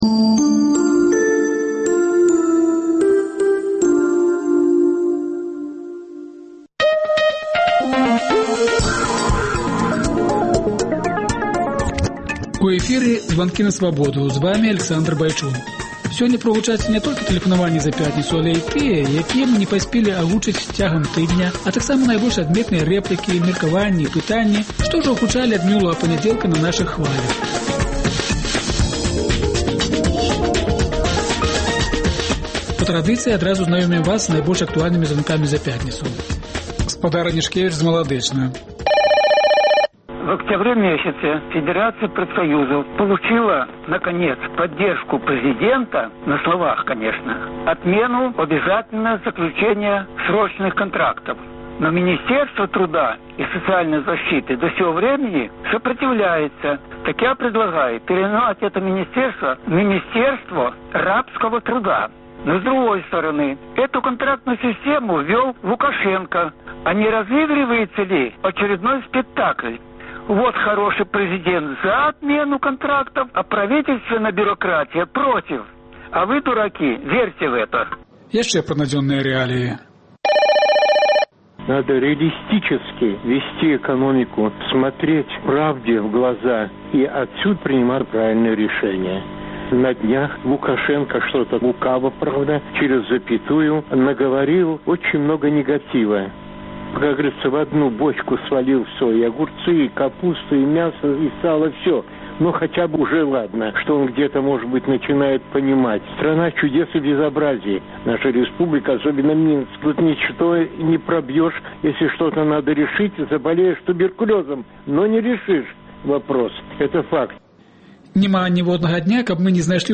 Тыднёвы агляд званкоў ад слухачоў "Свабоды"